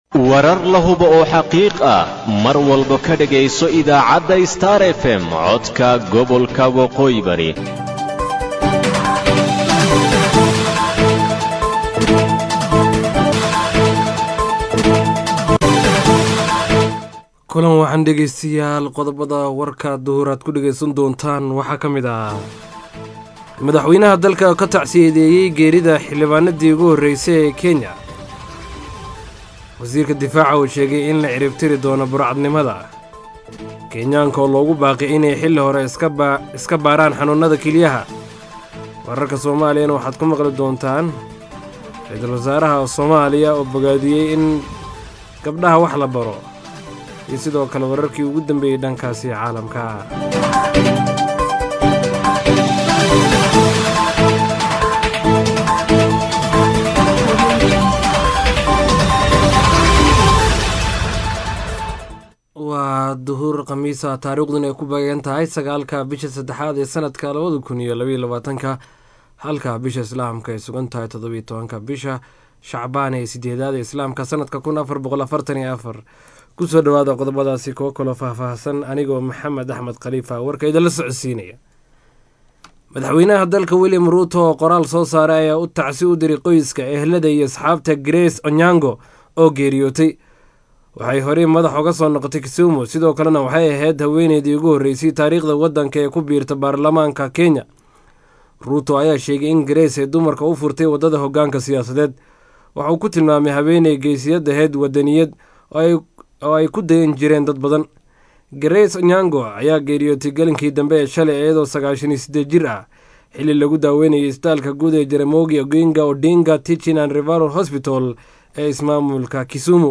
DHAGEYSO:WARKA DUHURNIMO EE IDAACADDA STAR FM